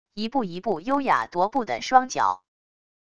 一步一步优雅踱步的双脚wav音频